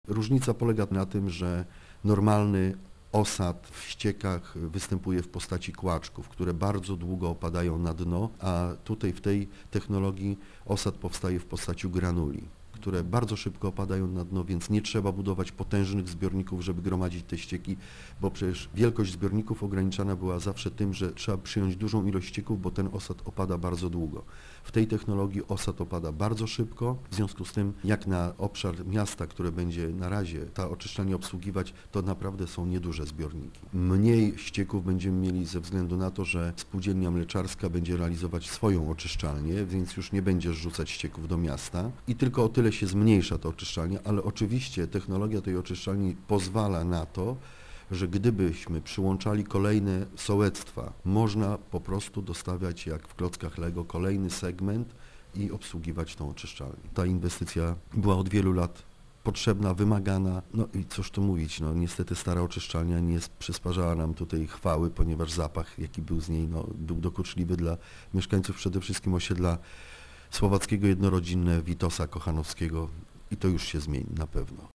Innowacyjność tego projektu polega na zastosowaniu technologii "nereda" firmy z Holandii, która słynie z nowatorskich rozwiązań na całym świecie - mówi burmistrz Ryk Jerzy Gąska: